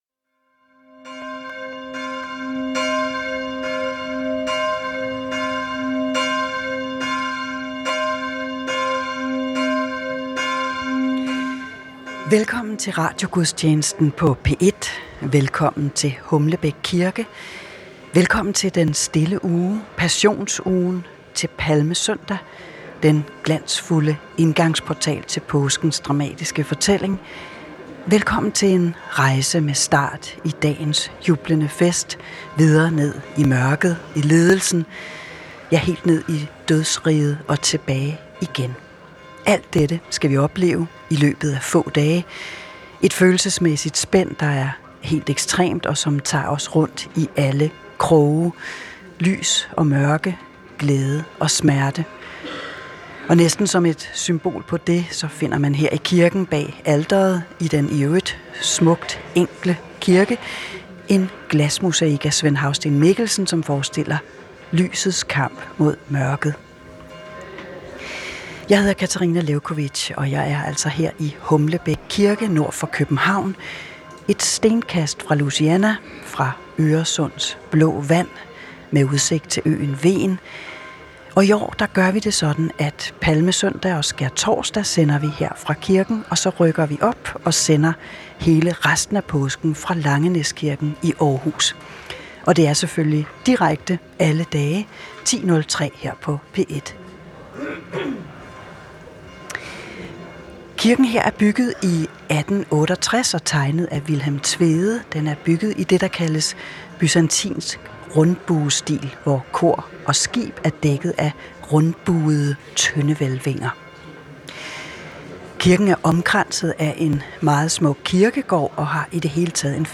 Direkte transmission af dagens højmesse fra en af landets kirker.